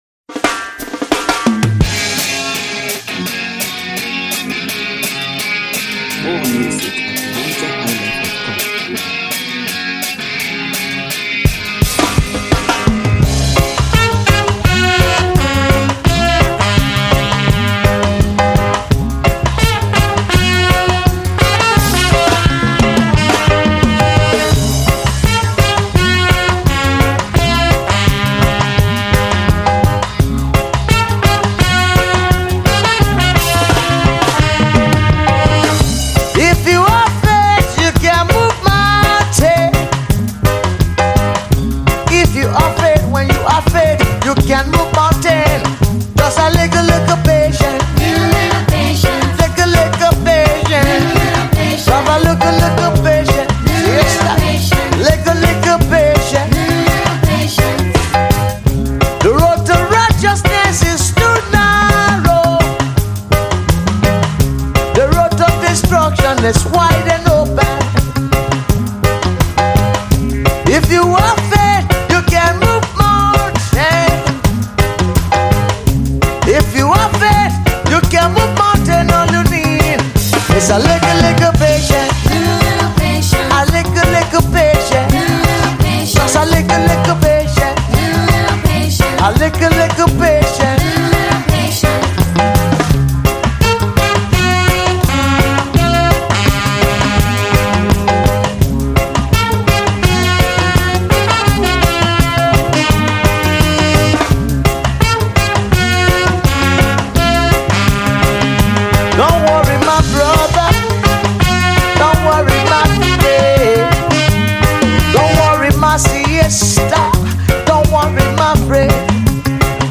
Home » Ragae
Wonderful Reggae Music
highly powerful Reggae Music